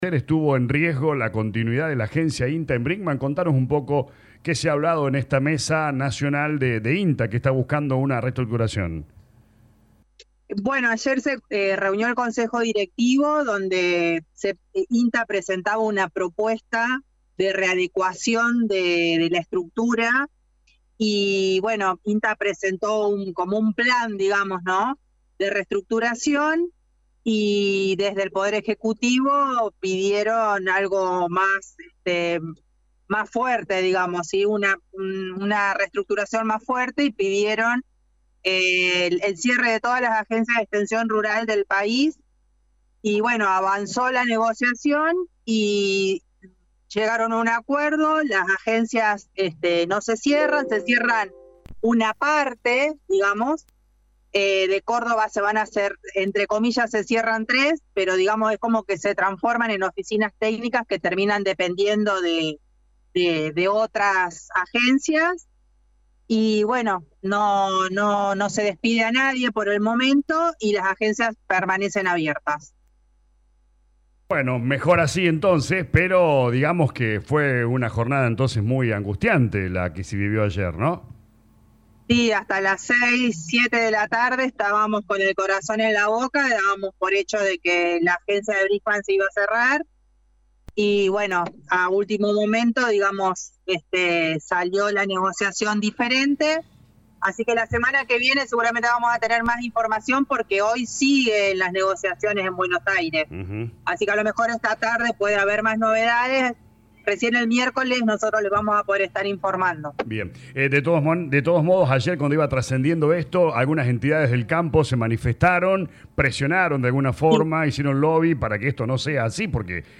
AUDIO DE LA RADIO 102.9 CON INFO DE CLARIN